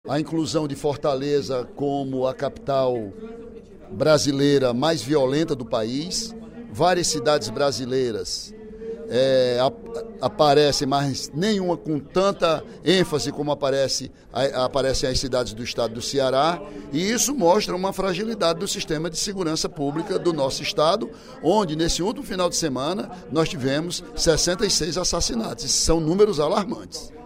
O deputado Ely Aguiar (PSDC) lamentou, durante o primeiro expediente da sessão plenária desta quarta-feira (07/06), o aumento do número de homicídios no Ceará.